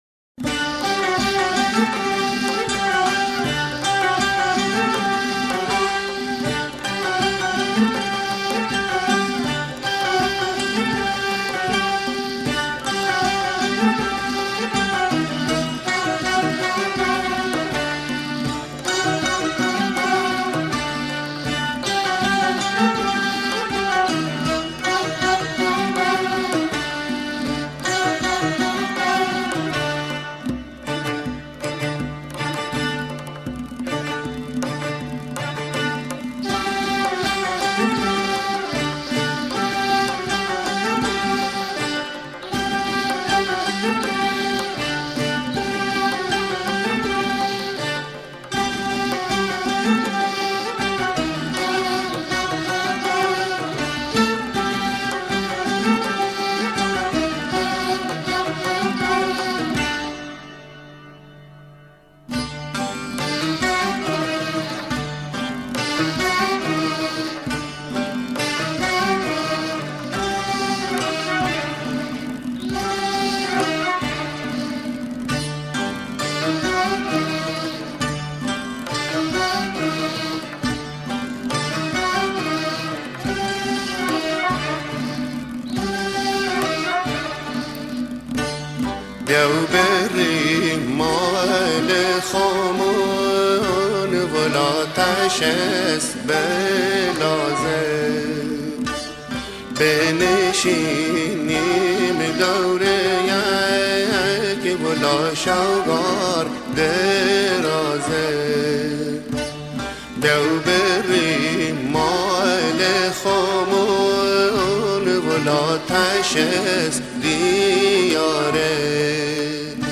• موسیقی لری